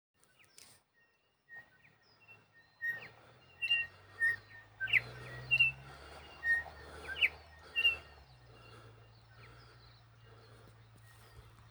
Sound of early morning McKenzie country